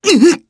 Fluss-Vox_Damage_jp_03.wav